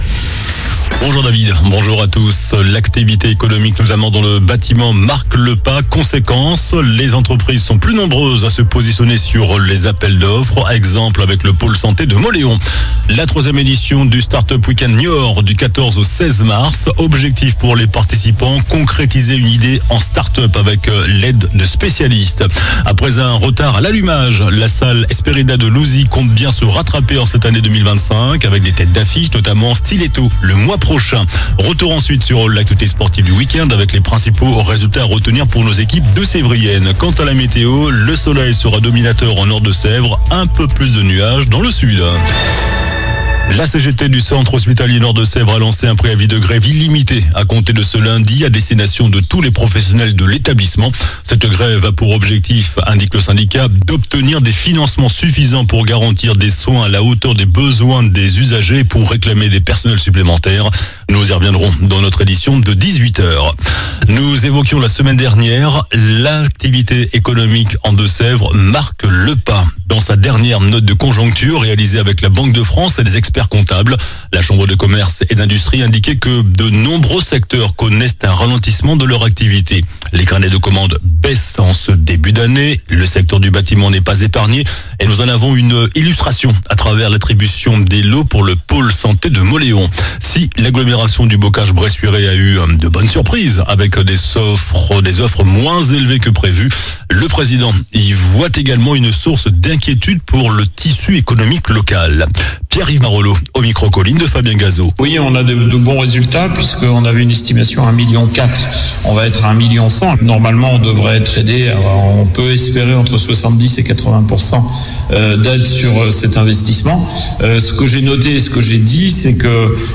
JOURNAL DU LUNDI 03 MARS ( MIDI )